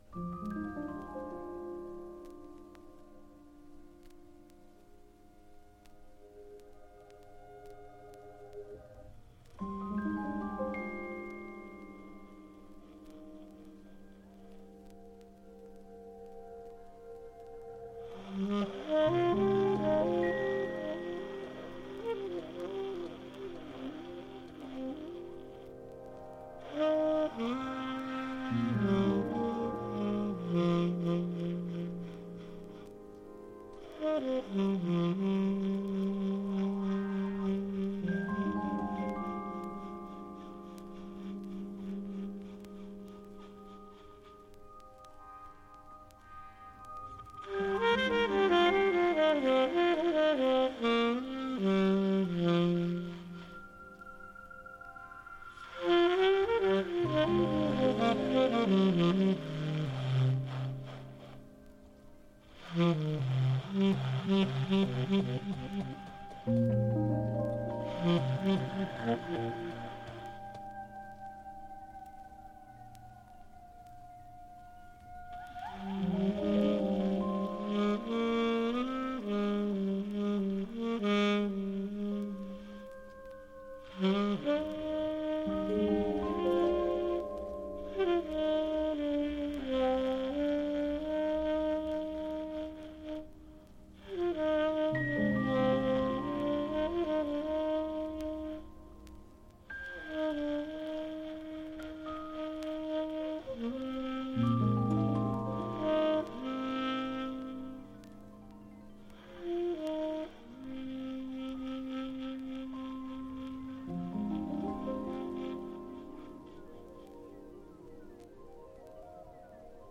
electronic
saxophone